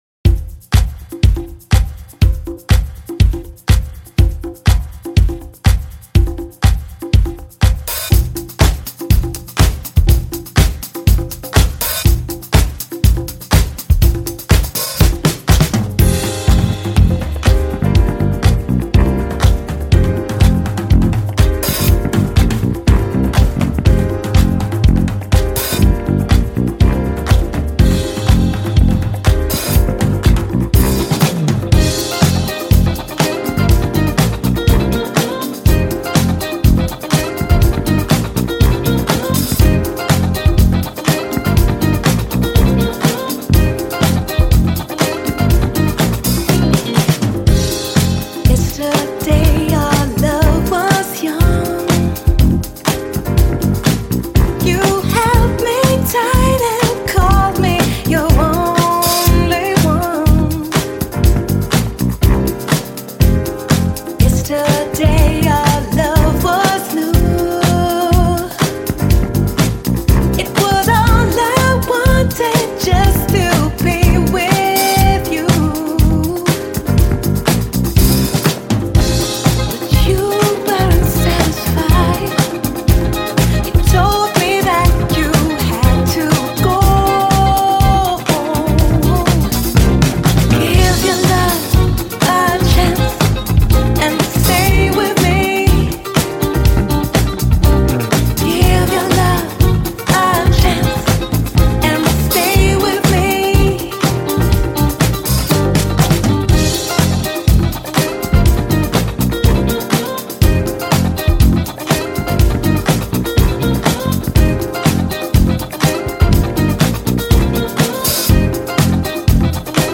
ジャンル(スタイル) DEEP HOUSE / JAZZ HOUSE / DISCO / SOULFUL HOUSE